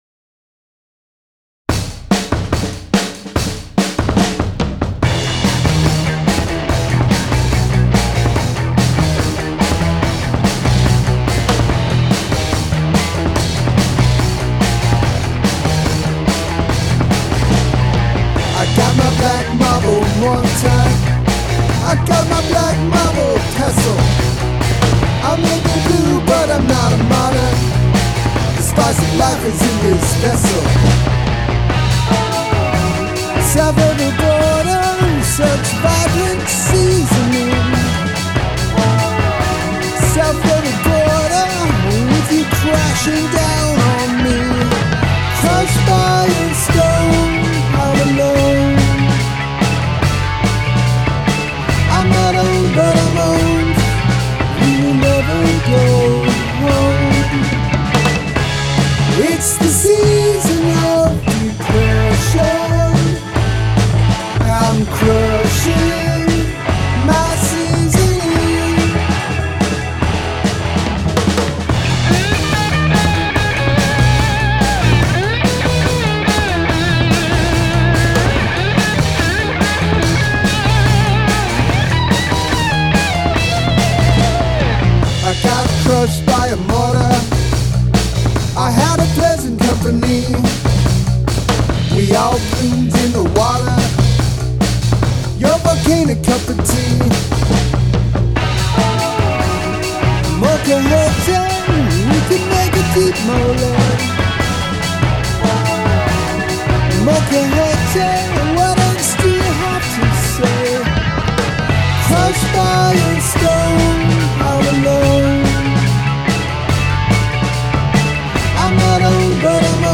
Use of Line Cliche
R*iff A: Emaj #11 (2 bar phrase x8)
Riff B: Bmaj 7 (1 bar phrase x8)